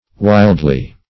Wildly \Wild"ly\, adv.